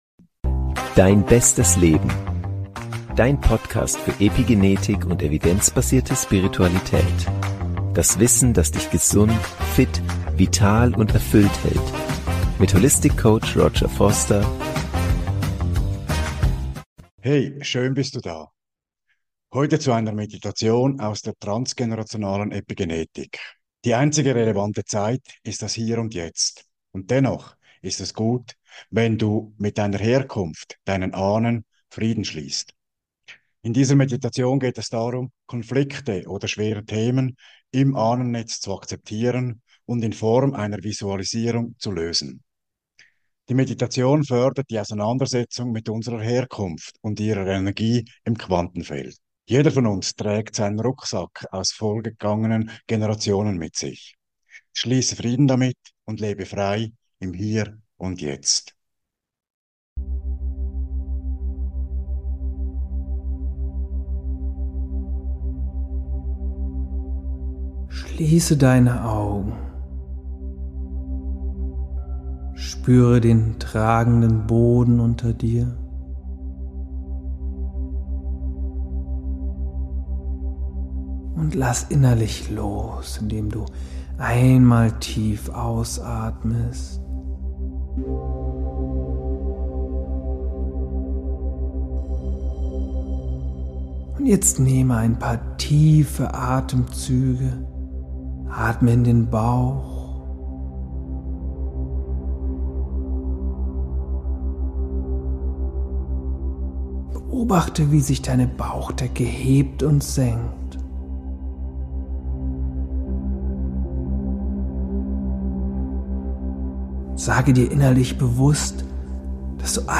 Beschreibung vor 1 Jahr In diesem Video erhältst Du eine geführte Meditation aus der transgenerationalen Epigenetik mit Binauralen Beats (6 Hz) im Frequenzbereich Theta, der Tiefenentspannung, Trance, Zugang zum Unterbewusstsein, Mentale Bilder sowie Vorstellungskraft unterstützt. Für ein optimales Hörerlebnis empfehlen sich Kopfhörer.